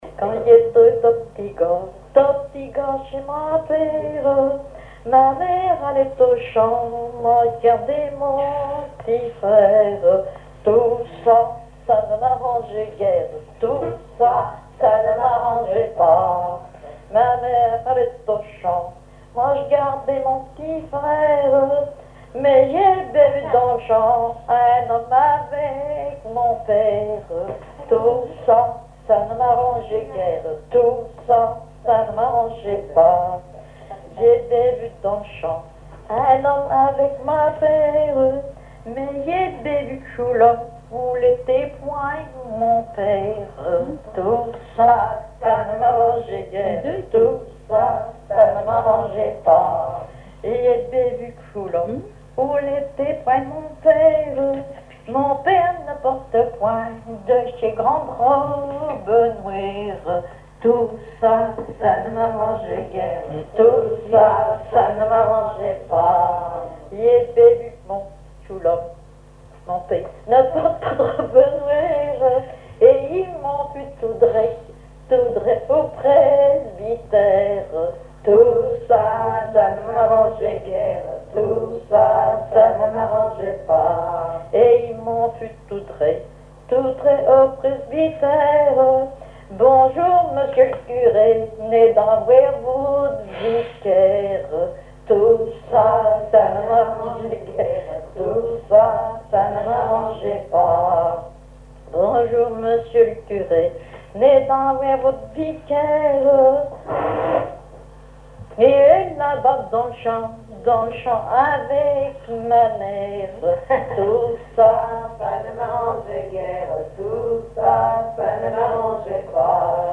Genre laisse
collecte en Vendée
Veillée de chansons
Pièce musicale inédite